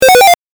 レトロゲーム （105件）
8bit奇妙1.mp3